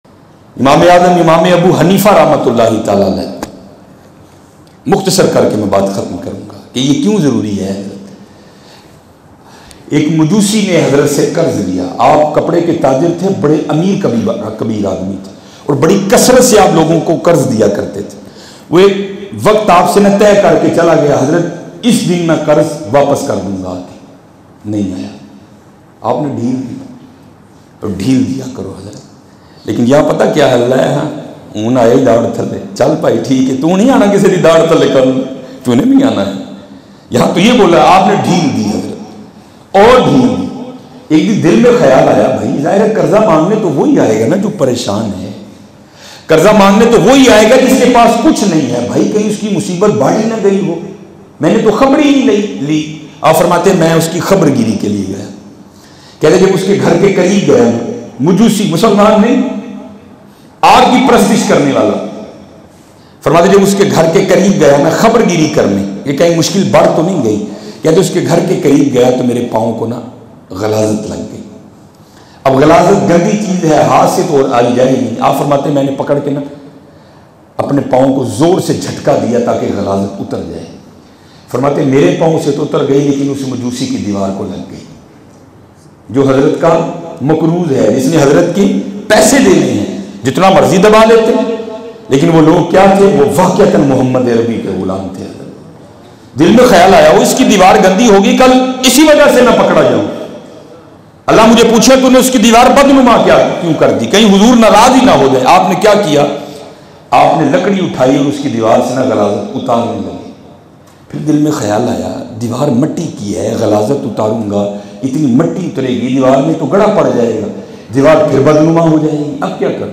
Imam e Azam Hazrat Abu Hanifa Lattest Bayan mp3